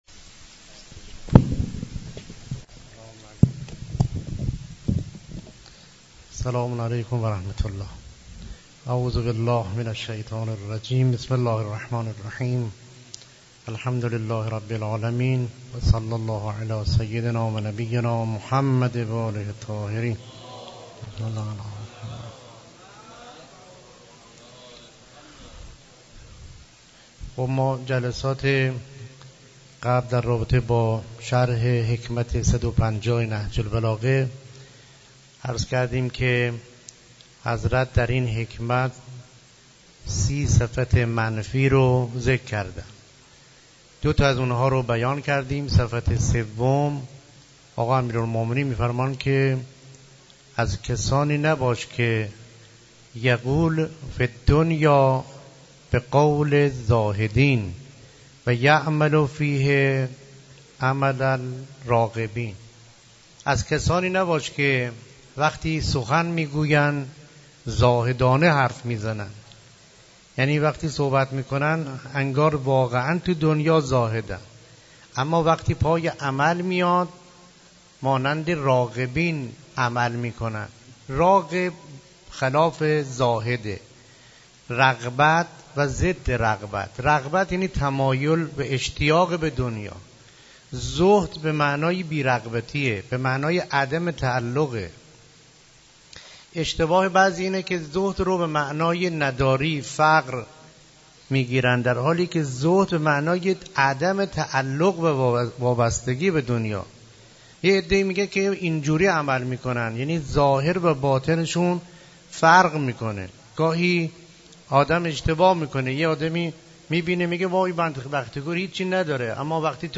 سخنرانی استاد محترم گروه معارف اسلامی دانشگاه
در مسجد دانشگاه کاشان